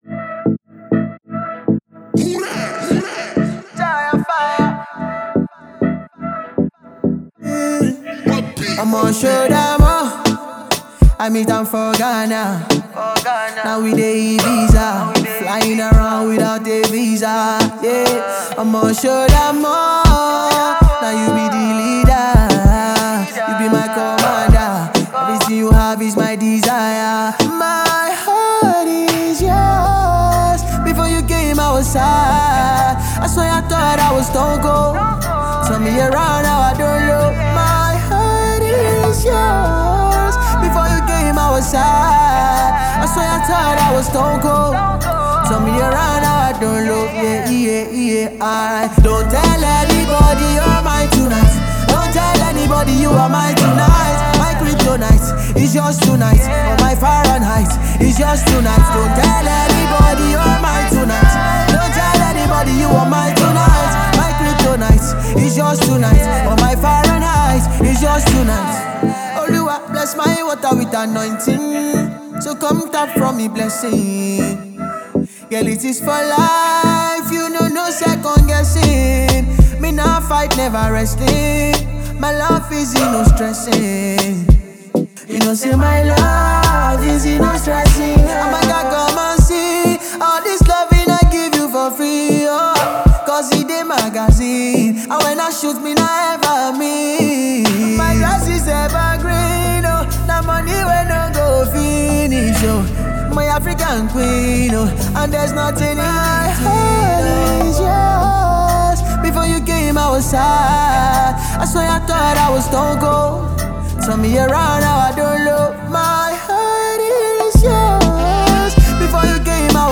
a dynamic blend of Afrobeat and Dancehall